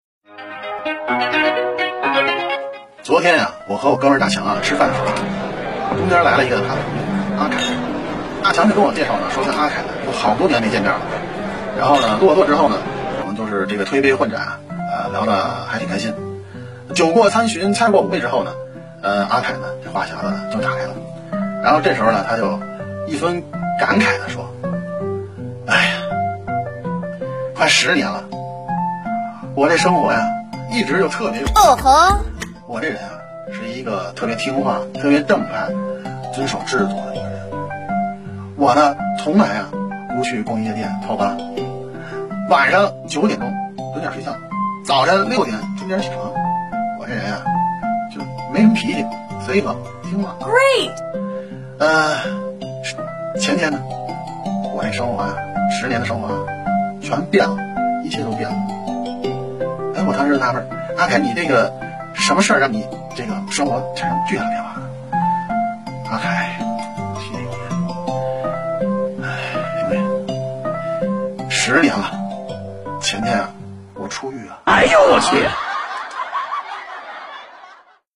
Genres: Comedy, Stand-Up